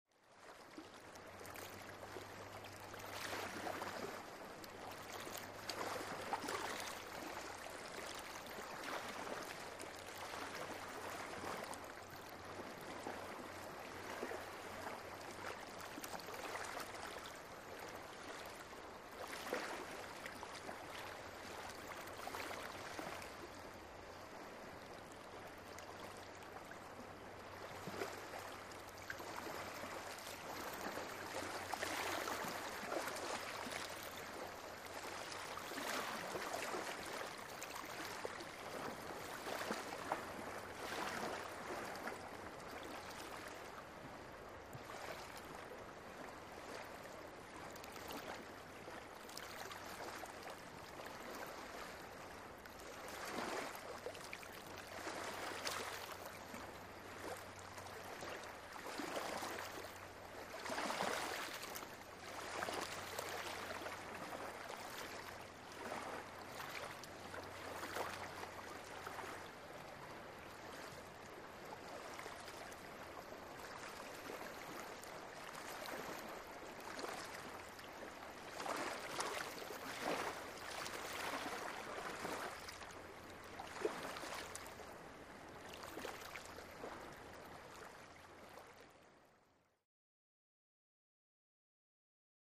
Very Light Steady Water Laps On A Calm Lake